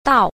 b. 到 – dào – đáo